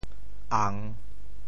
宴（讌） 部首拼音 部首 言 总笔划 23 部外笔划 16 普通话 yàn 潮州发音 潮州 ang3 文 中文解释 宴 <动> (形声。